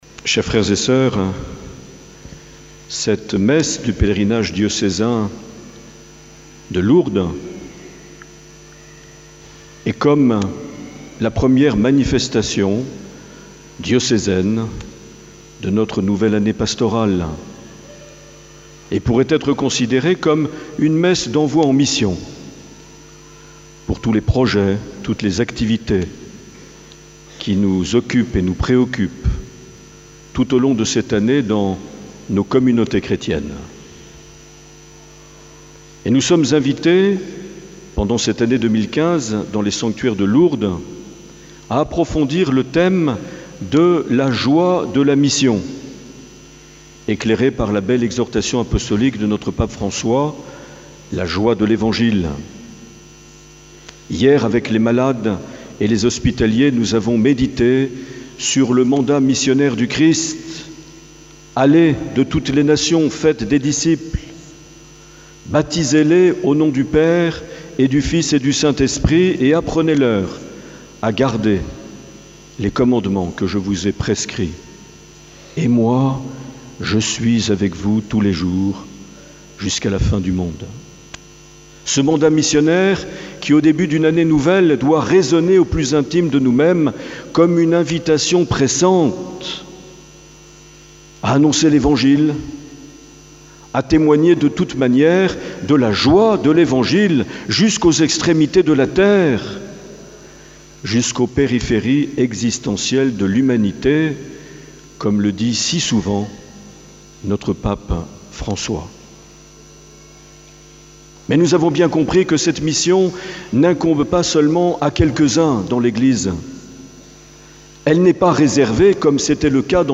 27 septembre 2015 - Lourdes Sainte Bernadette - Messe du pèlerinage diocésain
Accueil \ Emissions \ Vie de l’Eglise \ Evêque \ Les Homélies \ 27 septembre 2015 - Lourdes Sainte Bernadette - Messe du pèlerinage (...)
Une émission présentée par Monseigneur Marc Aillet